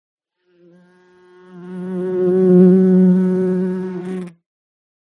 Звуки насекомых
Тихий гул пчелы, приближение, мягкая посадка